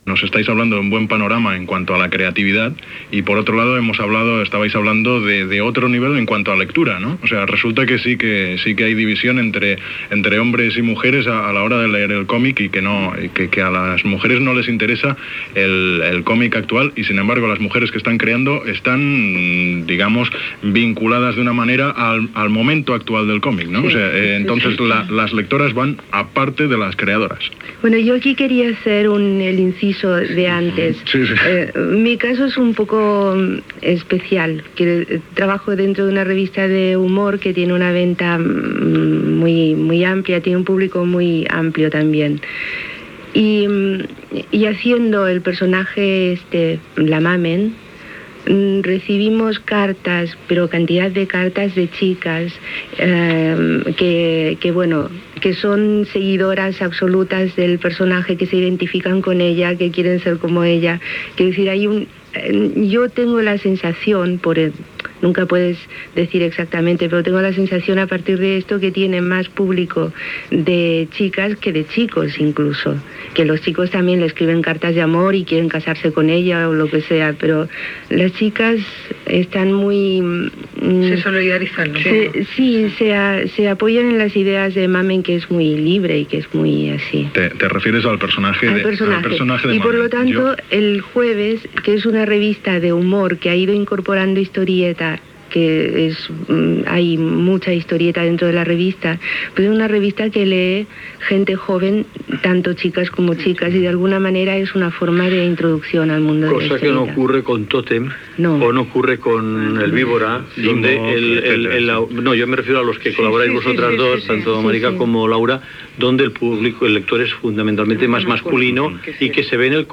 Taula rodona sobre les autores del còmic.